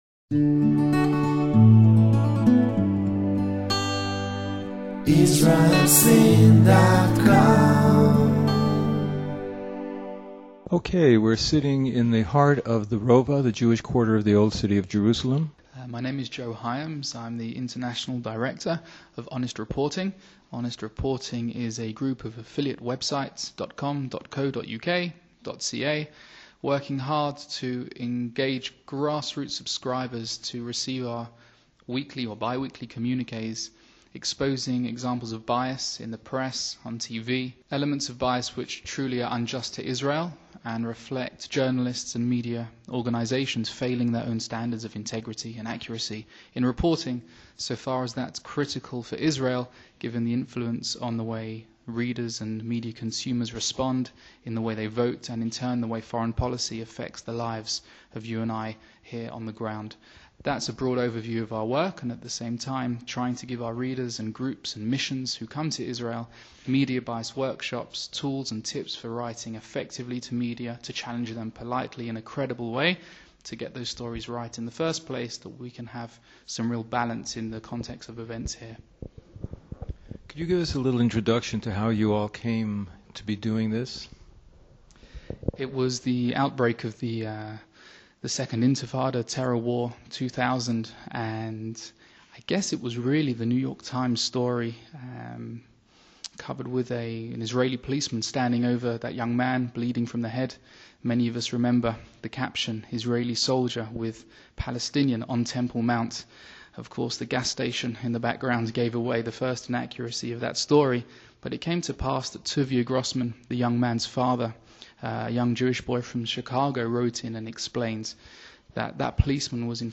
Honest Reporting Interview.